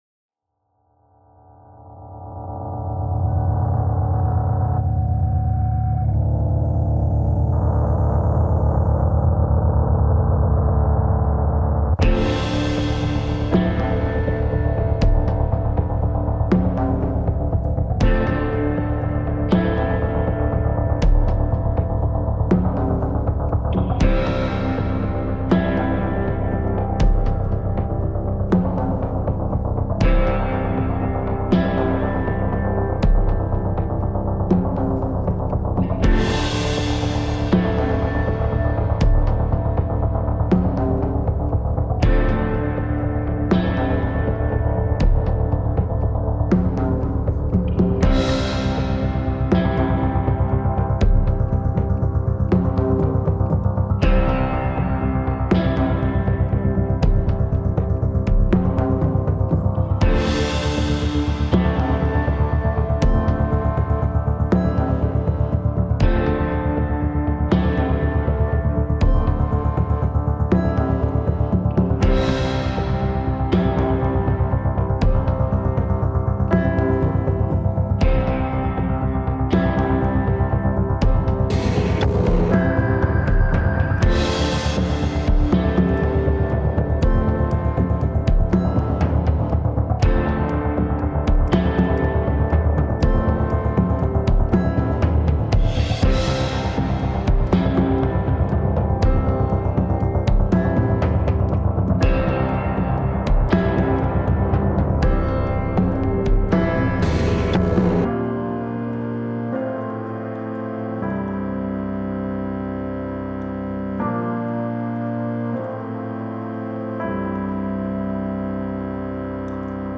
This is the music only [no lyrics] version of the song.